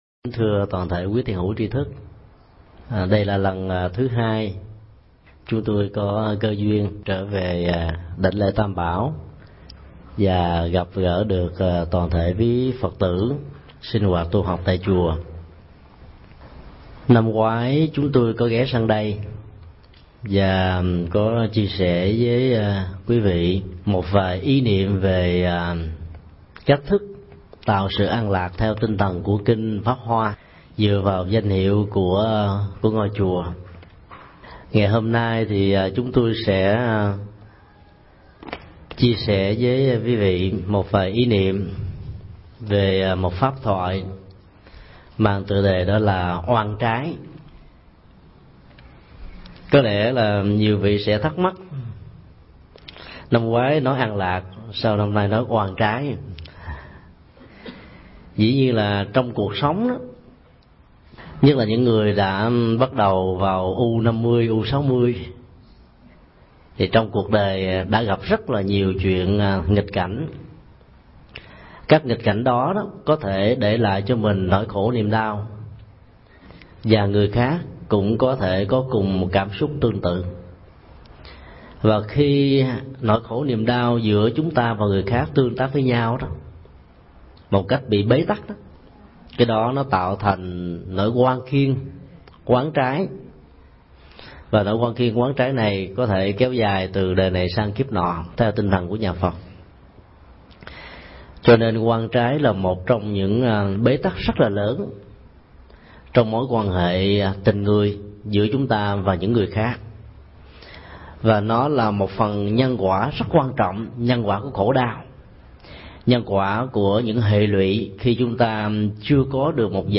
Mp3 Pháp Thoại Nhận diện oan trái – Thầy Thích Nhật Từ Giảng tại Chùa An Lạc, San Jose, ngày 11 tháng 6 năm 2005